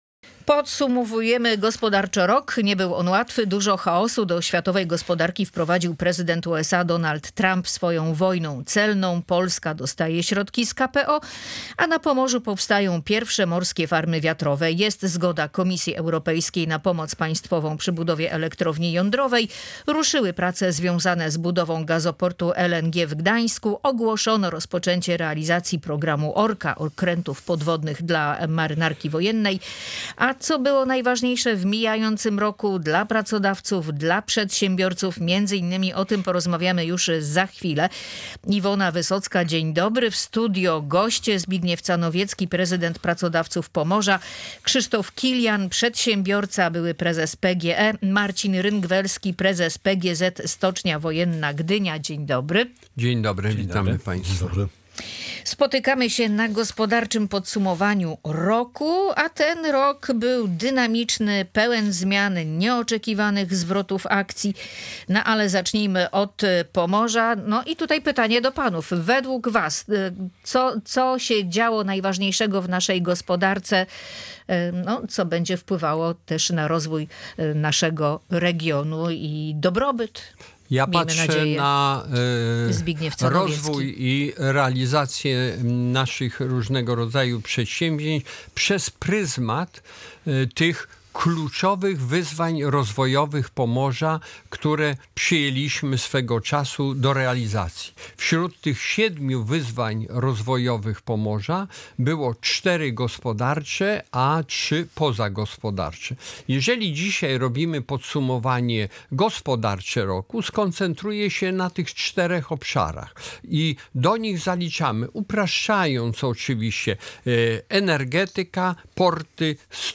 W audycji "Ludzie i Pieniądze" eksperci gospodarczo podsumowali mijający rok, który nie był łatwy.